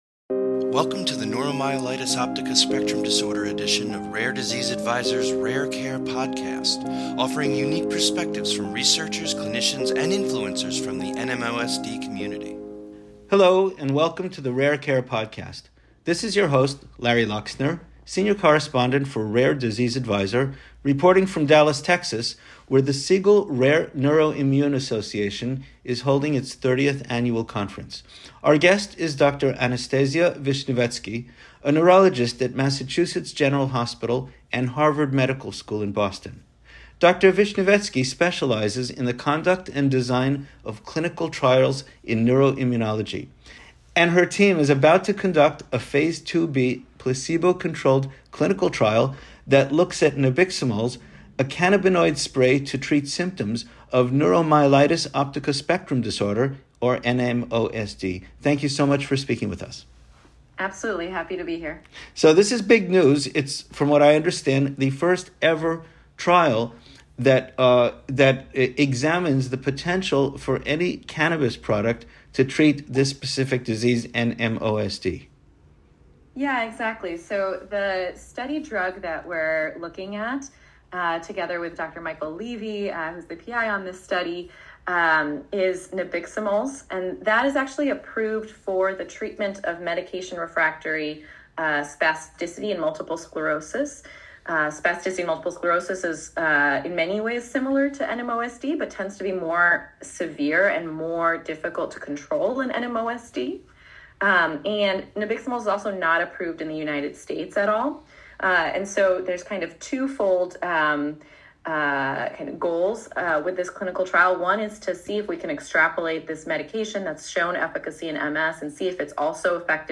Rare Disease Advisor's Rare Care Podcast features exclusive interviews with experts and stakeholders from the rare disease community.